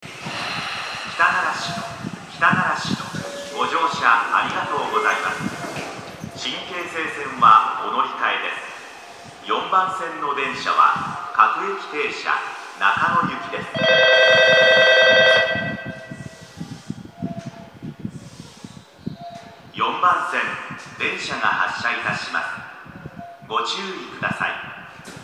駅放送
到着発車 響きやすく高音は割れる 風の音が入ってしまっています。